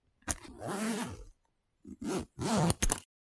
拉链
描述：我拉链和拉开我的夹克。
标签： 拉链 压缩和解 夹克 外套 解压缩 压缩 解压缩
声道立体声